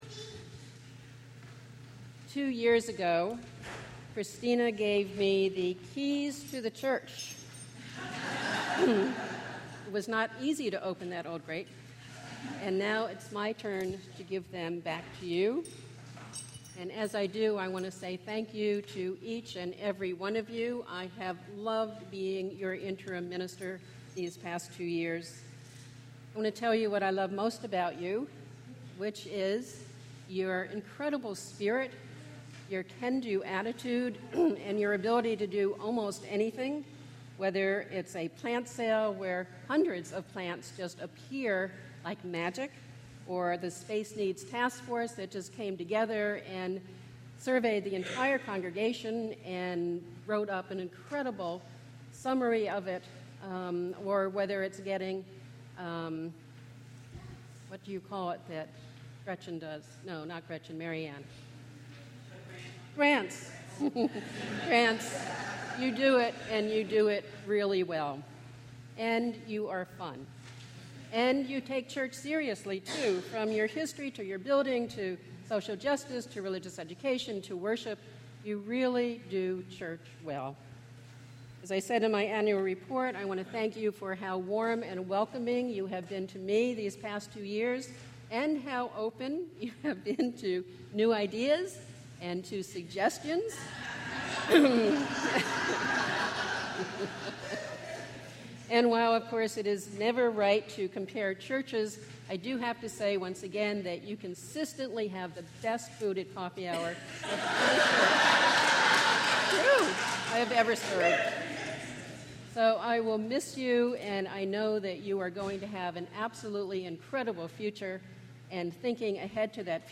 Flower Communion